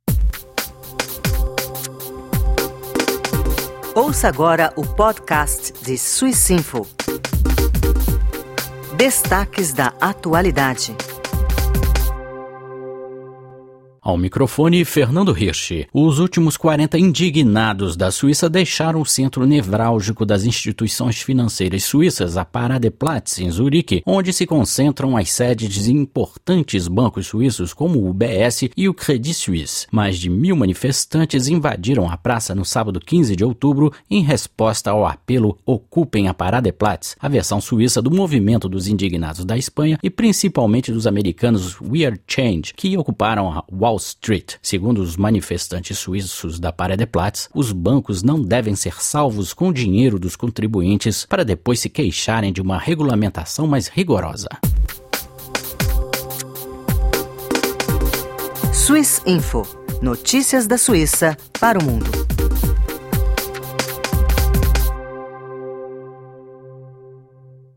Mais de mil manifestantes ocuparam a praça em protesto à influência do setor financeiro na política do país.